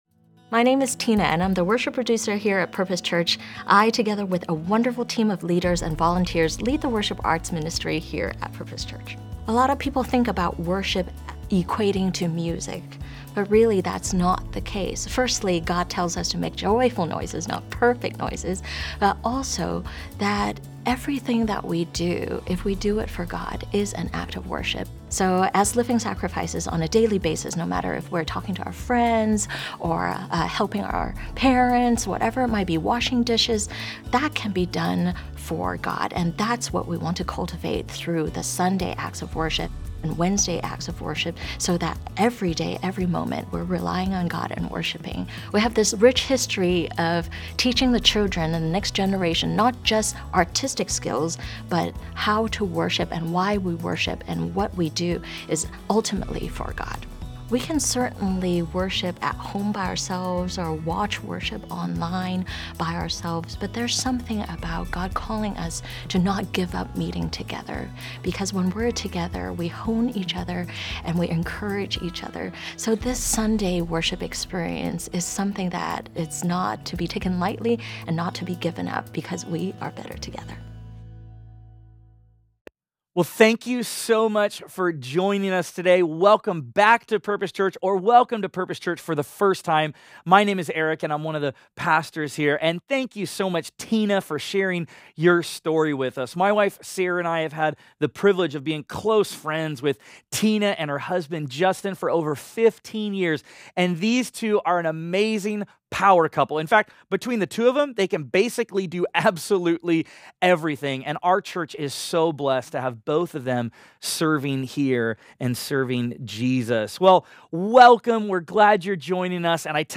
Study Guide | Download Audio File Traditional Worship (In-Person Service)